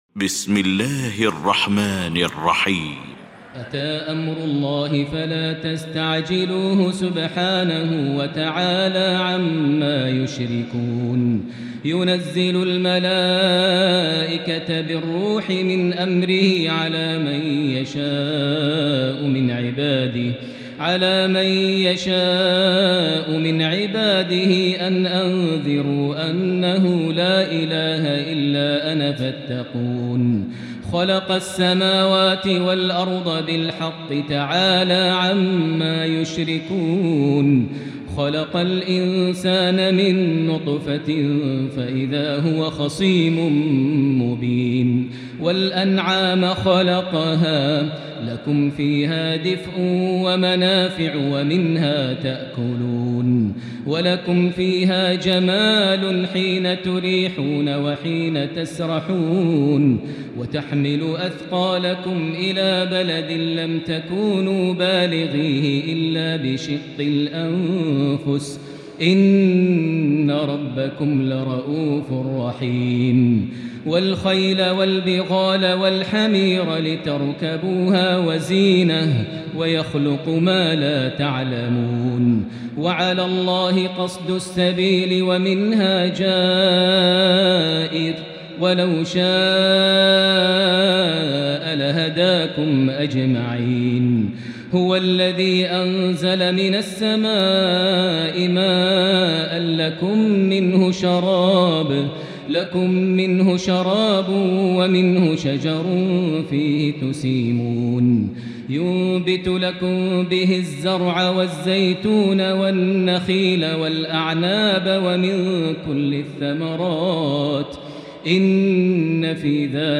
المكان: المسجد الحرام الشيخ: معالي الشيخ أ.د. بندر بليلة معالي الشيخ أ.د. بندر بليلة معالي الشيخ أ.د. عبدالرحمن بن عبدالعزيز السديس فضيلة الشيخ ماهر المعيقلي النحل